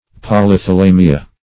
Meaning of polythalamia. polythalamia synonyms, pronunciation, spelling and more from Free Dictionary.
Search Result for " polythalamia" : The Collaborative International Dictionary of English v.0.48: Polythalamia \Pol`y*tha*la"mi*a\, n. pl.